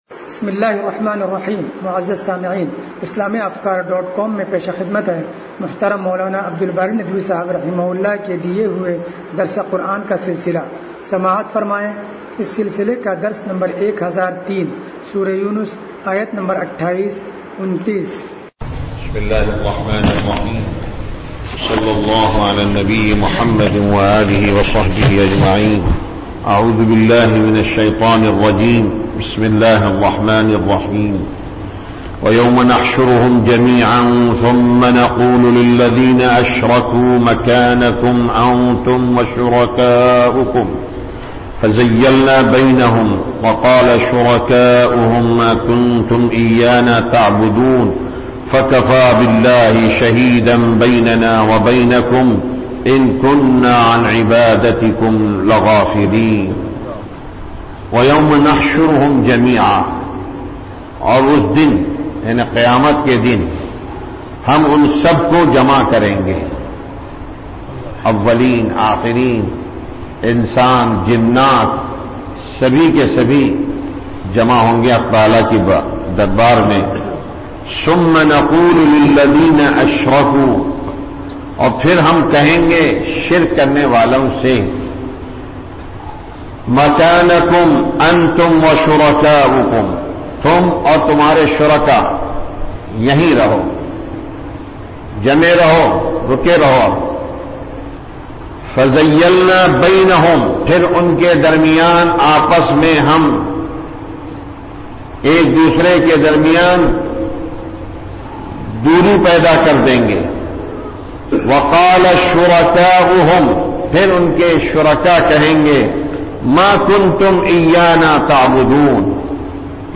درس قرآن نمبر 1003